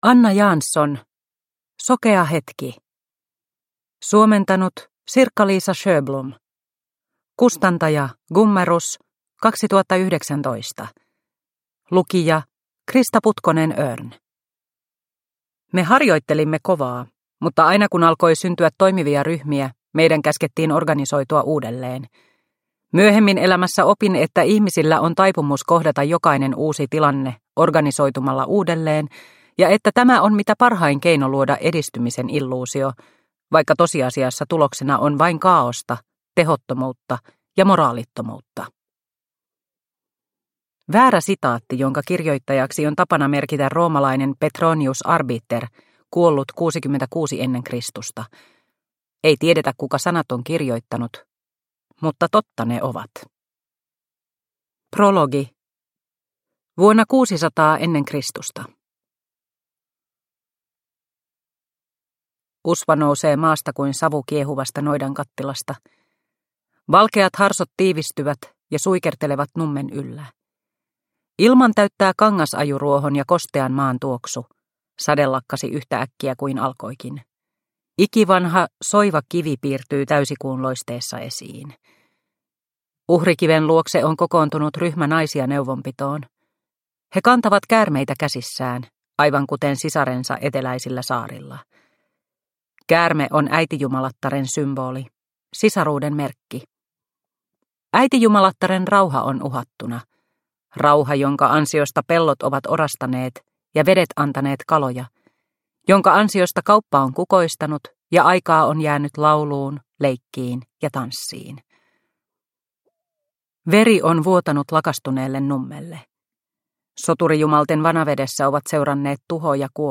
Sokea hetki – Ljudbok – Laddas ner